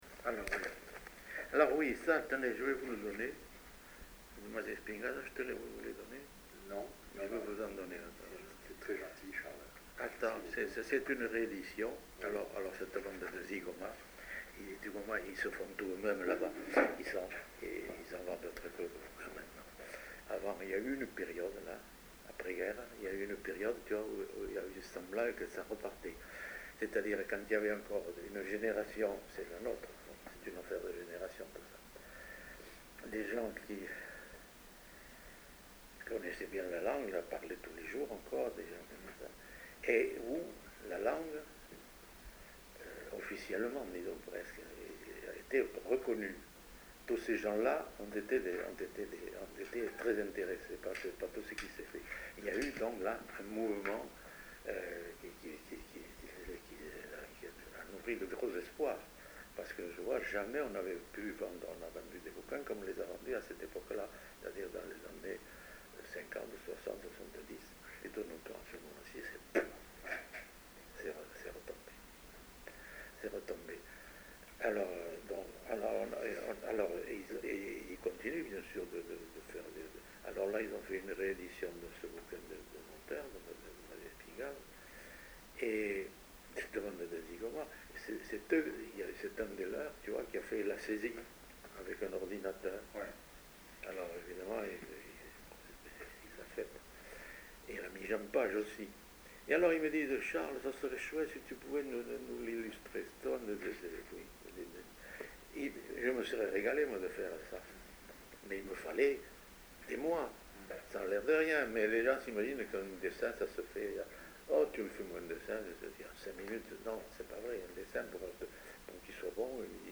Lieu : Saint-Sauveur
Genre : témoignage thématique